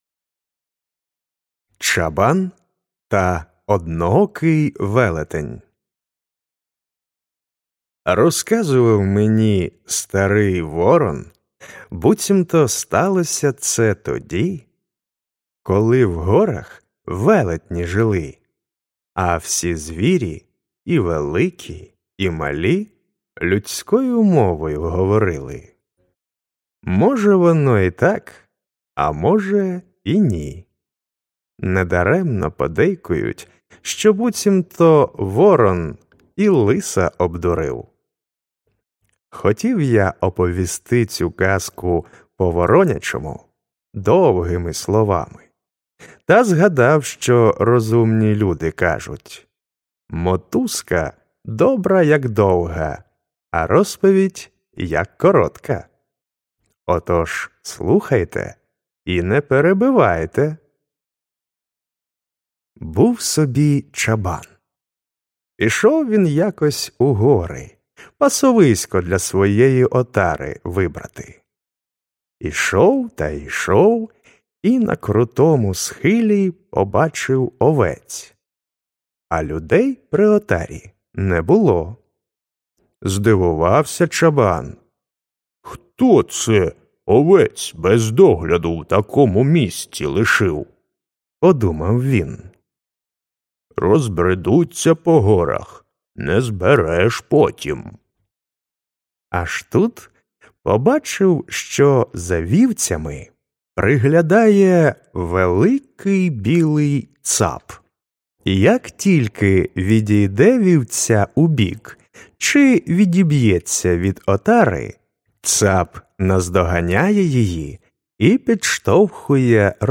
Аудіоказка Чабан та одноокий велетень